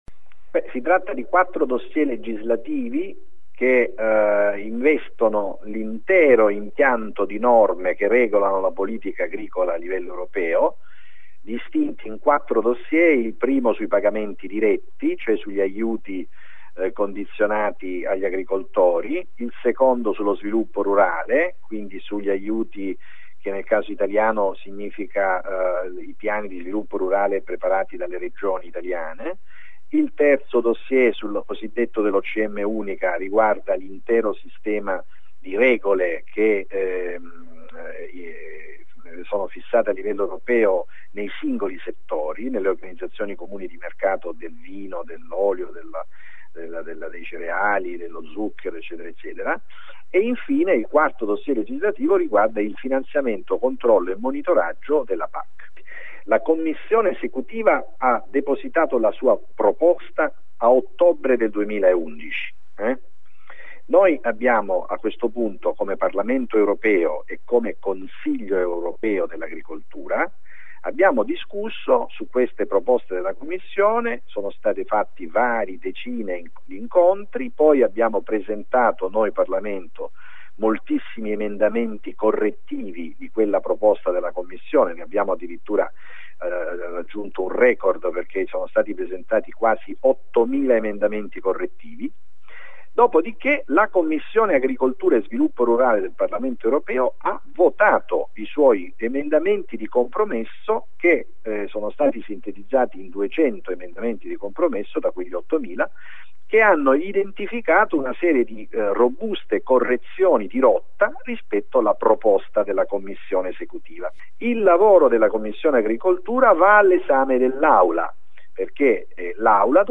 Paolo De Castro, Presidente della Commissione europea per le Politiche Agricole, spiega in questa intervista a Kmzero i contenuti della Pac